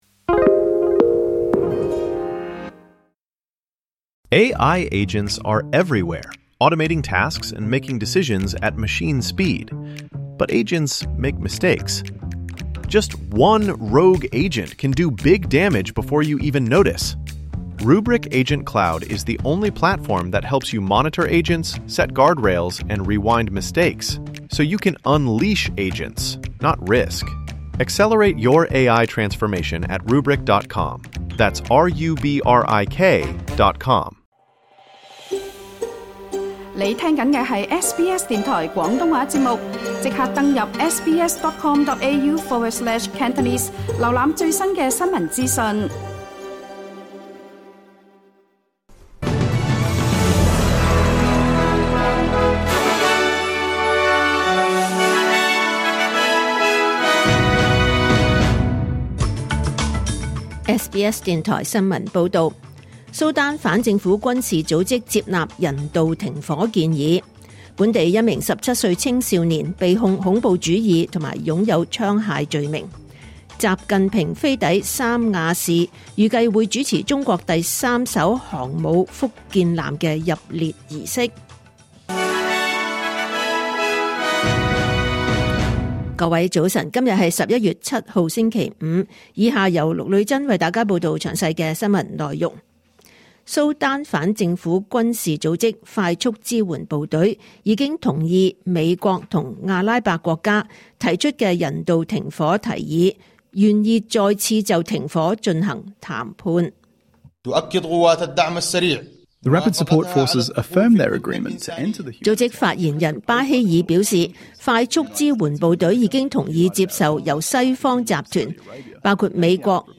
2025年11月7日 SBS 廣東話節目九點半新聞報道。